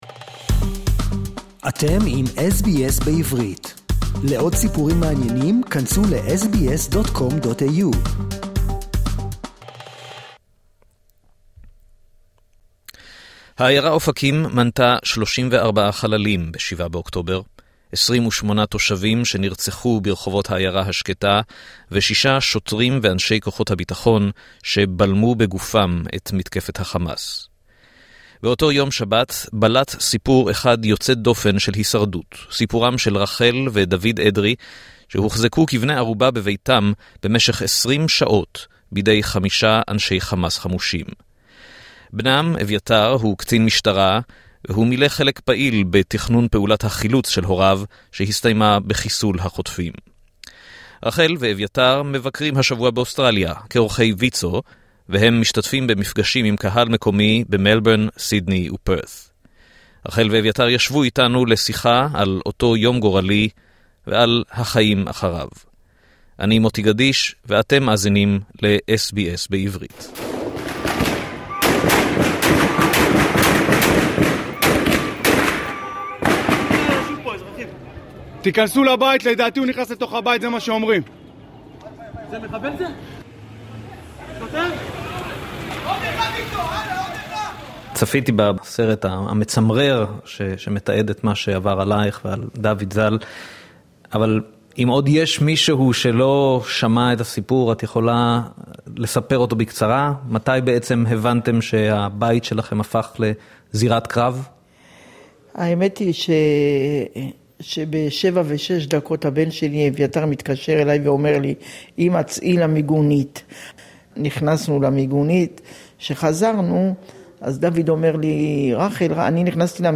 בריאיון
שיחה בלתי נשכחת עם אישה שלא מפסיקה לדבר — כדי לרפא את עצמה ואת הסובבים אותה.